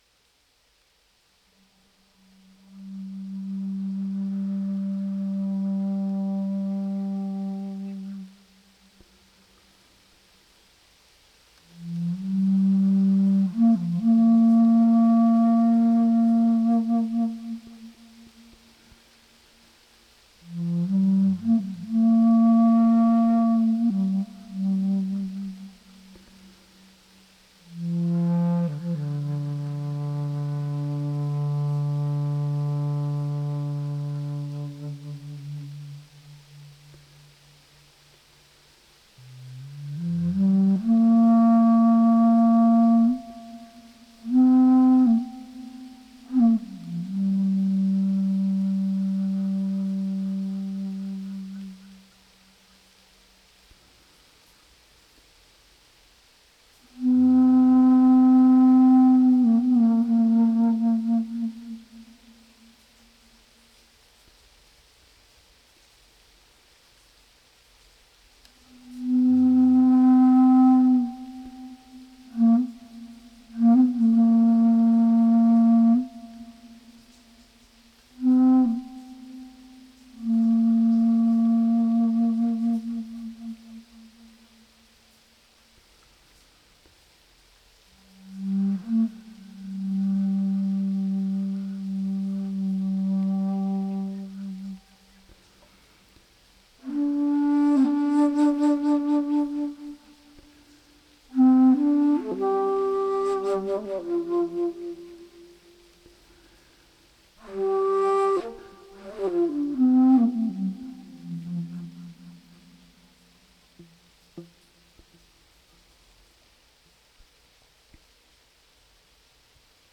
Beautiful, gentle rain this morning recorded out the window along with my bass flute.